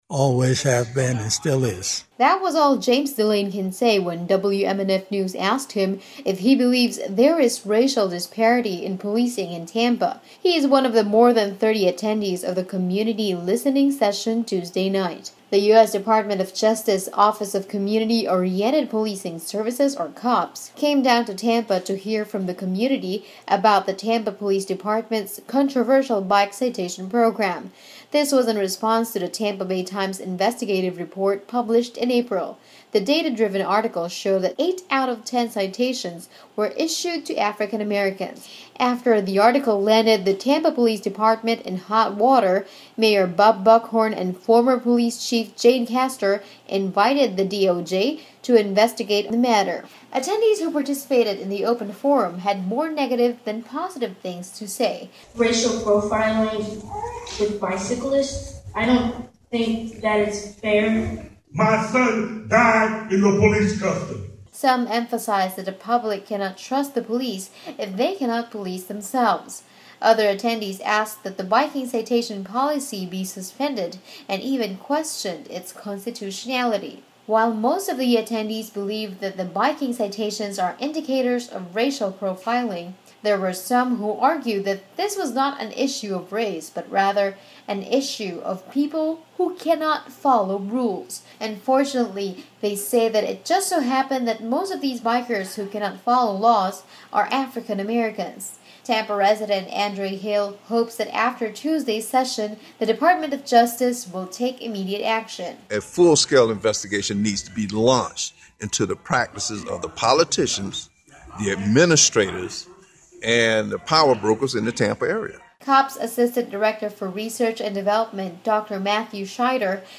The US Department of Justice is getting community input about the Tampa Police policy of handing out bicycle citations – overwhelmingly to black residents. They held a community listening session at the Hillsborough Community College Ybor Campus Tuesday night.